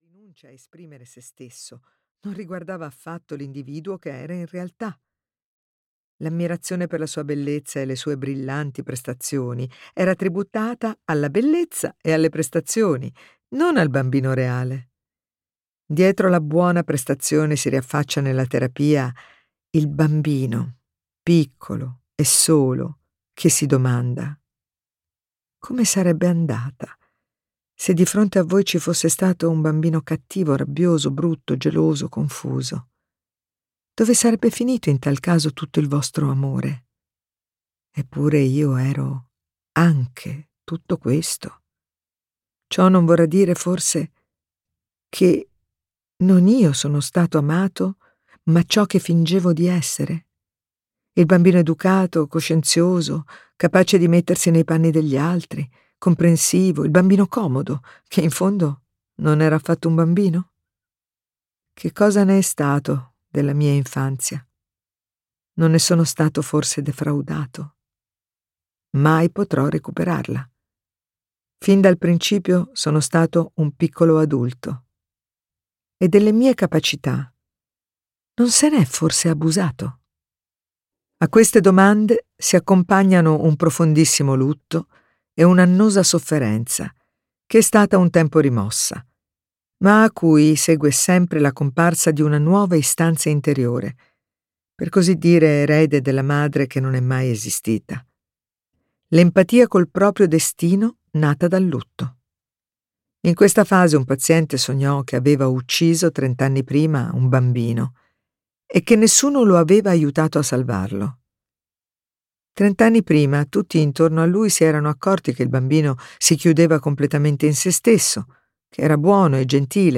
"Il dramma del bambino dotato e la ricerca del vero sé" di Alice Miller - Audiolibro digitale - AUDIOLIBRI LIQUIDI - Il Libraio